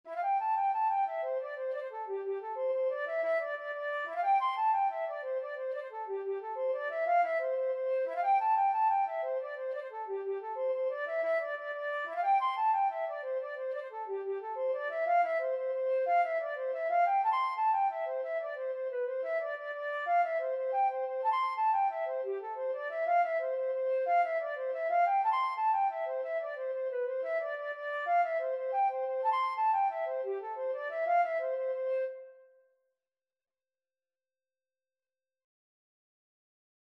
Flute version
6/8 (View more 6/8 Music)
G5-C7
Traditional (View more Traditional Flute Music)